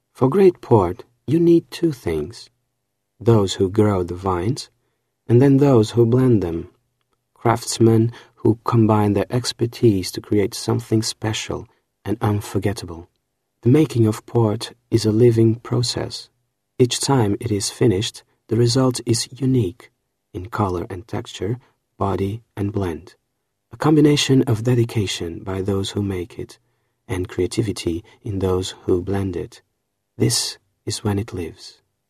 Portuguese, Male, Home Studio, 20s-30s